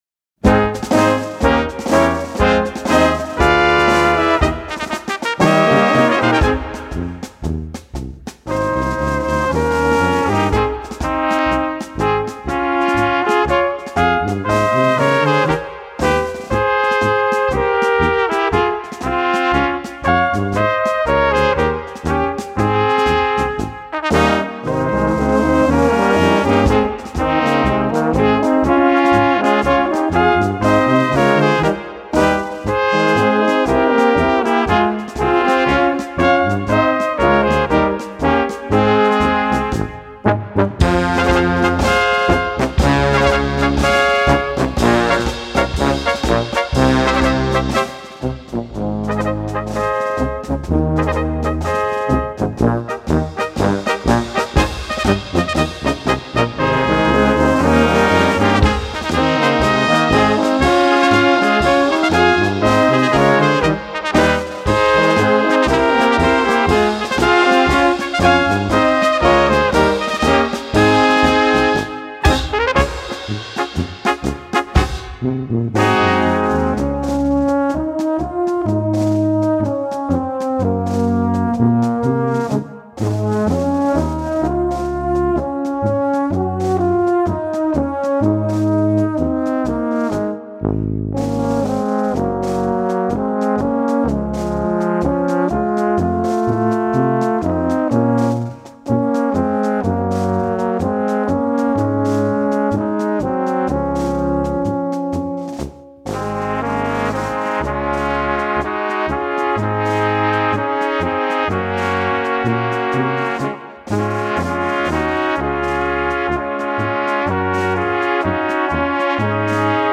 Marsch
Mittelstufe